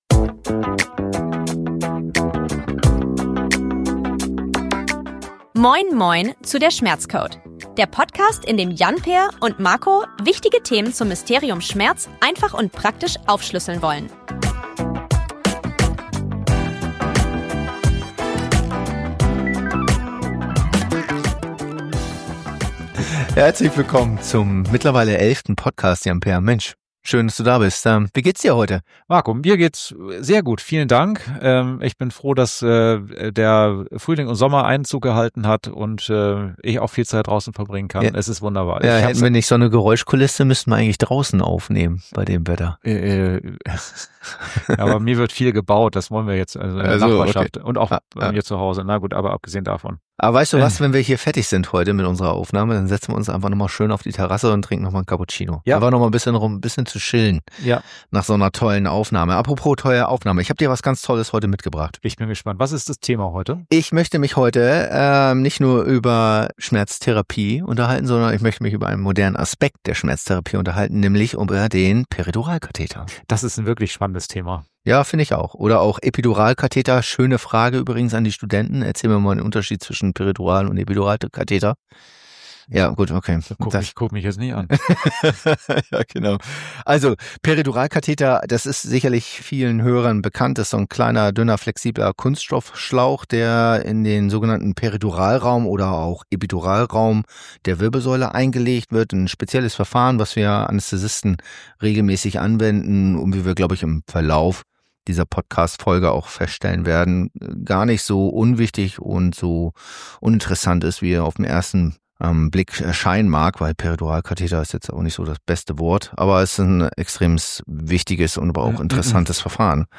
Das Gespräch beginnt mit einer kurzen Einführung, gefolgt von einem angenehmen kurzen und privaten Austausch, bevor wir in die Tiefe des Themas eintauchen.